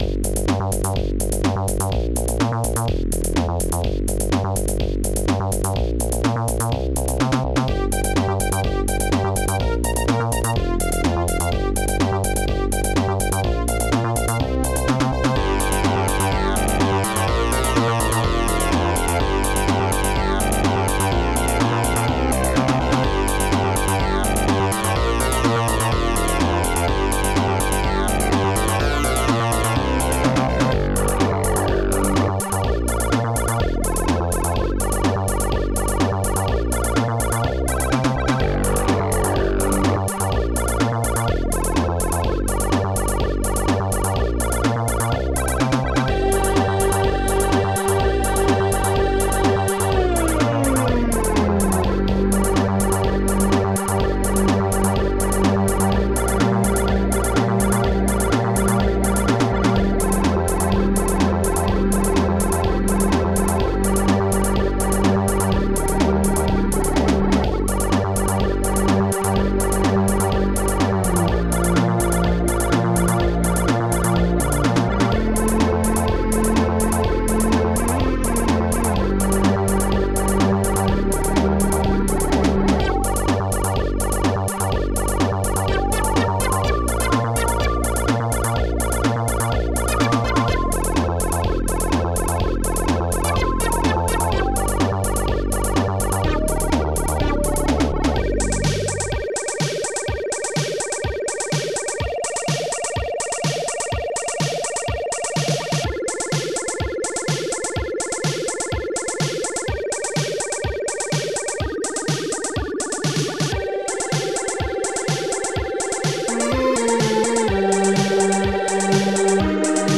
Instruments heavysynth bigbow jahrmarkt1 hallbrass strings6 pingbells jahrmarkt2 monobass synbrass bassdrum2 popsnare1 popsnare2 hihat2